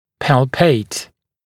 [pæl’peɪt][пэл’пэйт]пальпировать